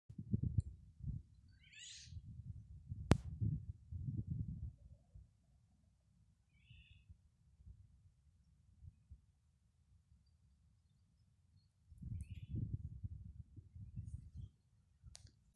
Vālodze, Oriolus oriolus
StatussDzied ligzdošanai piemērotā biotopā (D)
PiezīmesPēdējā laikā visas dienas laikā dzirdami šādi saucieni. Vairāk koku augšdaļā.